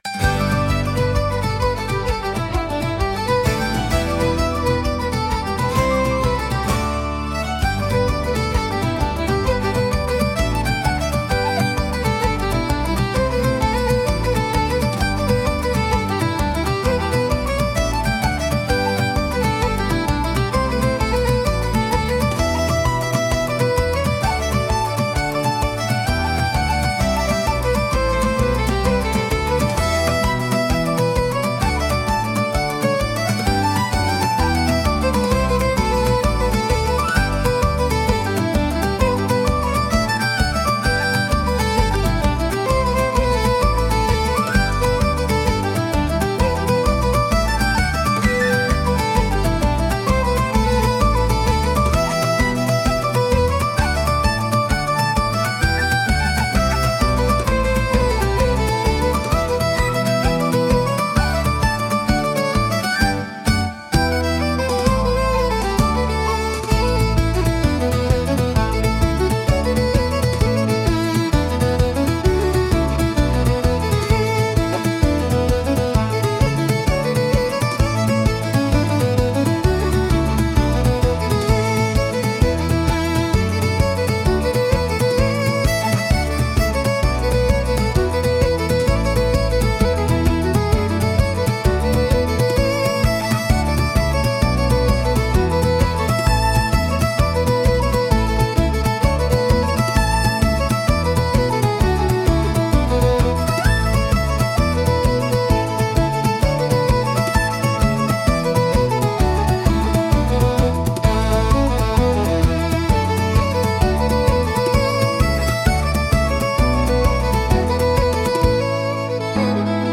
神秘的で豊かな民族色があり、物語性や異国情緒を演出するシーンに効果的なジャンルです。